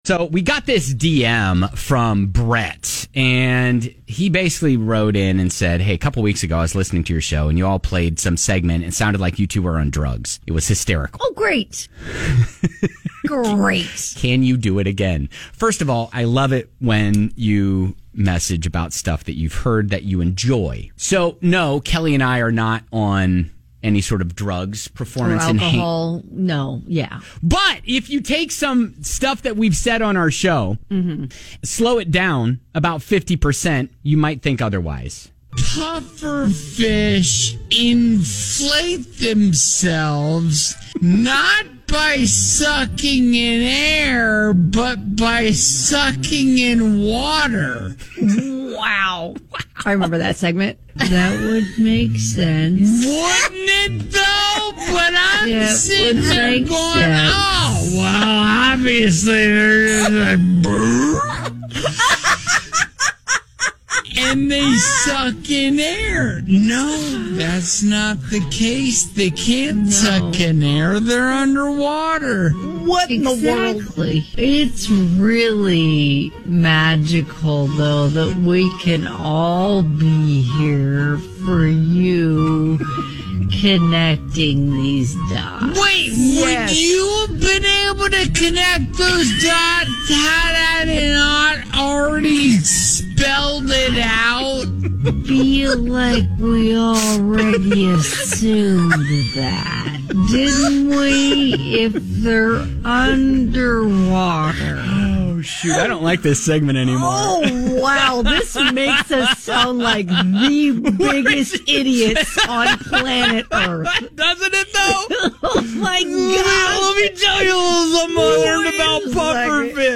Our conversations can be weird at normal speed. But slow them down by 50%, and well, we get texts like this: